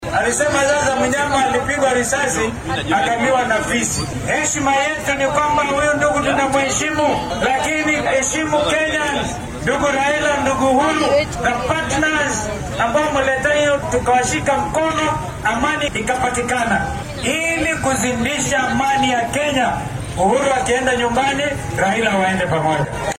Hoggaamiyaha Wiper oo ka mid ah madaxda isbeheysiga OKA ee maanta dalladda Mount Kenya Forum kula kulmay deegaanka Thika ee ismaamulka Kiambu ayaa carrabka ku adkeeyay in heshiiskii sanadkii 2018-kii dhexmaray madaxweyne Uhuru Kenyatta iyo madaxa xisbiga ODM Raila Odinga uu dalka xasilooni buuxda u horseeday.